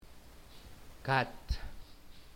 pronunciación
Often pronounced with short vowel.